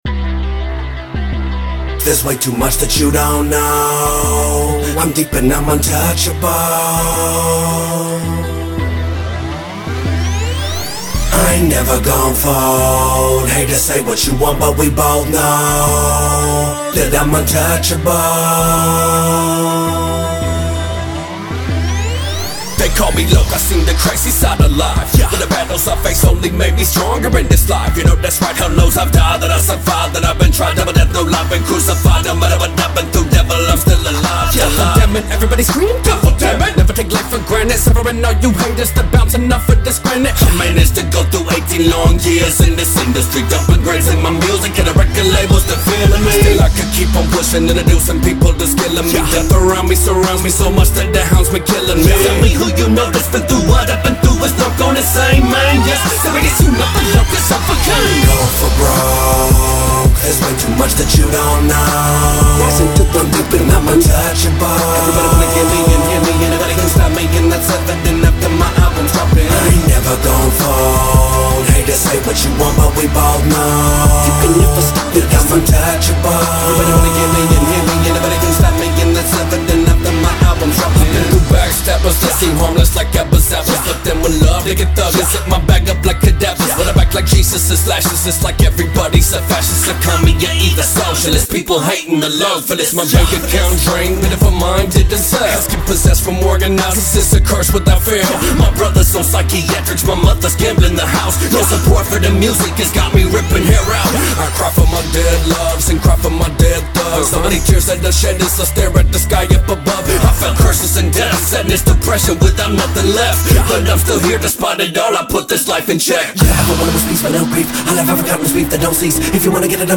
local hip hop artists